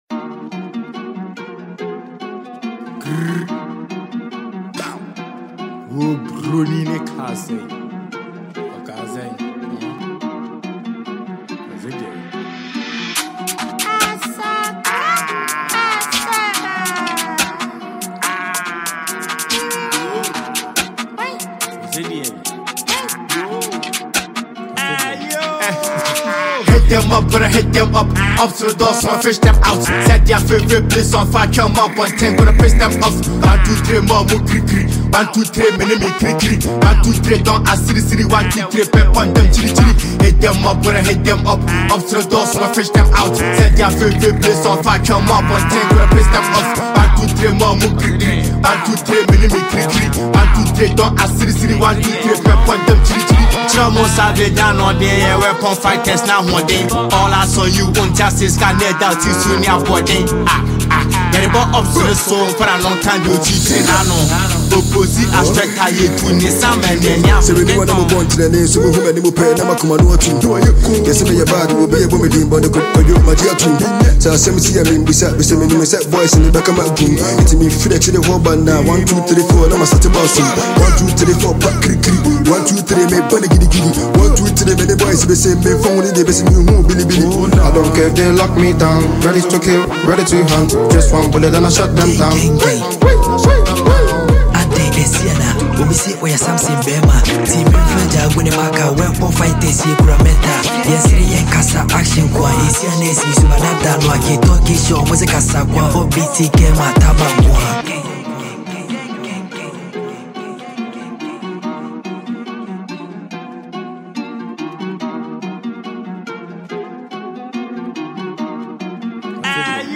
a Ghanaian asakaa rapper